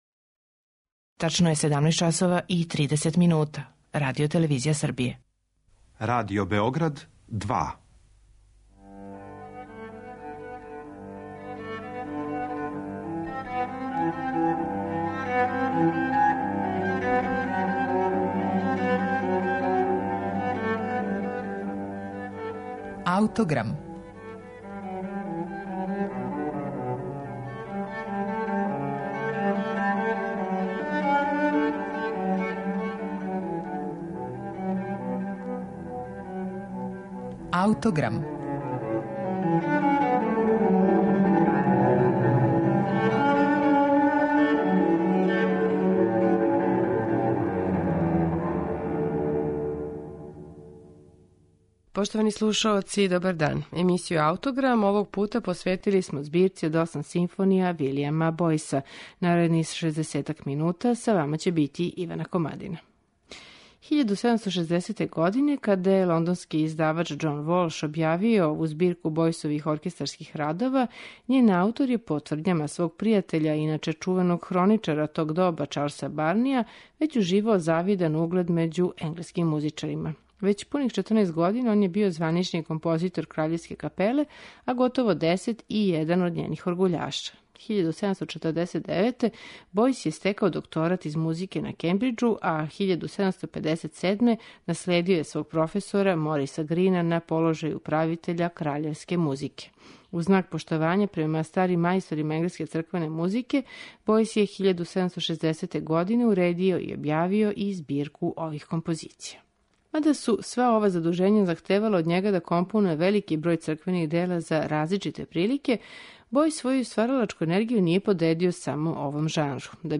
Део те музике преточио је у осам симфонија, којима смо посветили данашњи Аутограм , а које ћемо слушати у интерпретацији оркестра Академије Saint Martin in the Fields и диргента Невила Маринера.